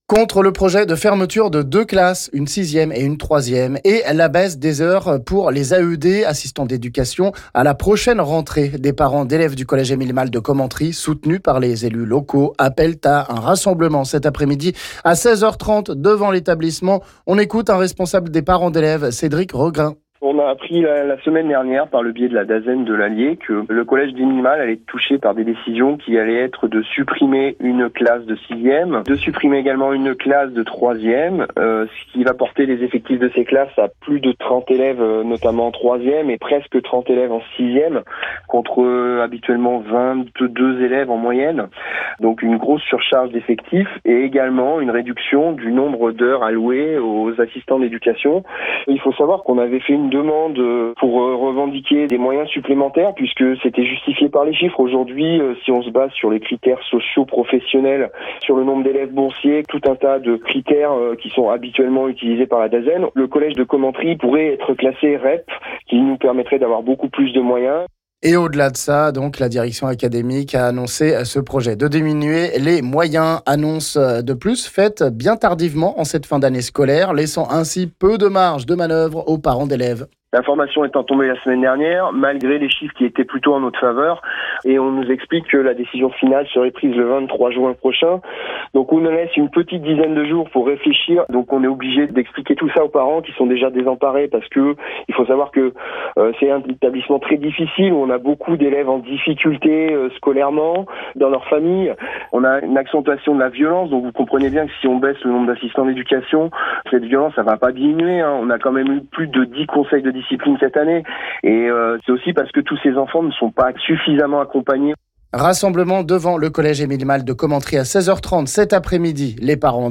On en parle ici avec un responsable des parents d'élèves